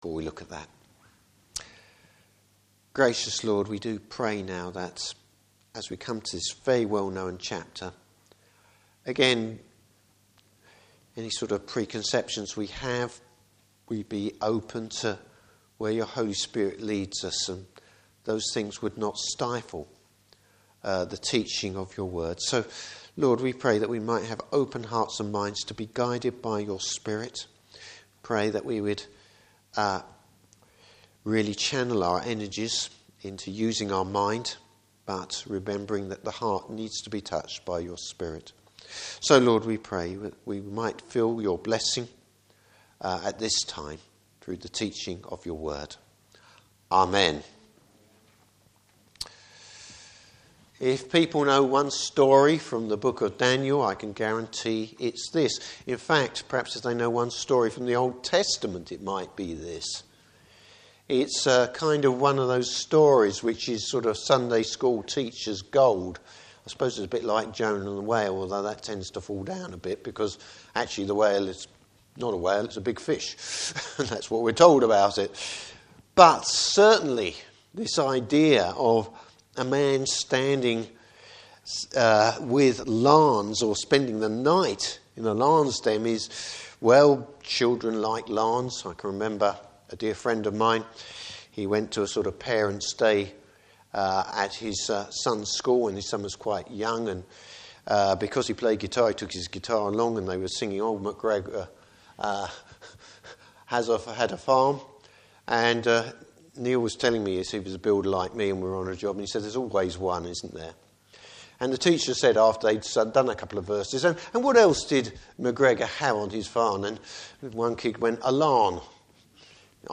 Service Type: Evening Service How faithfulness to the Lord over the years helped Daniel face the ultimate test!